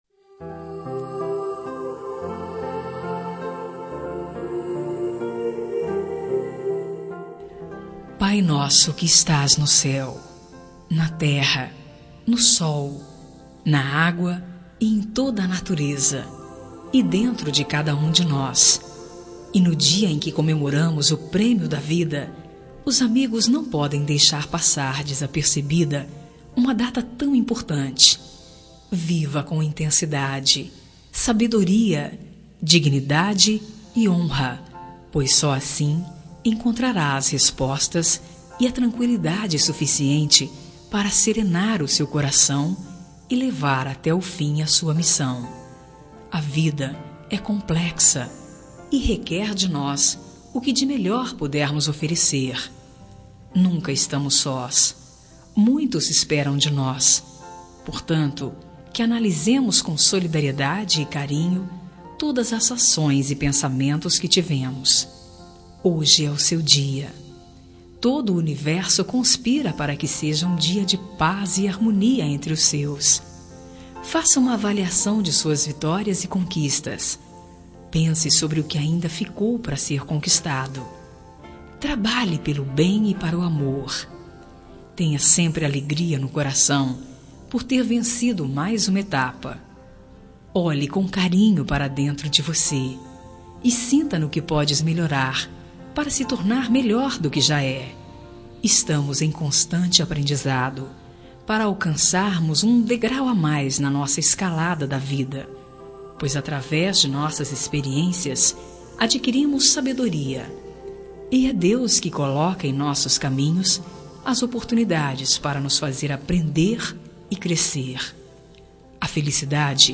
Aniversário Religioso – Voz Feminina – Cód: 34890 – Pai Nosso
34890-religiosa-fem.m4a